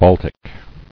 [Bal·tic]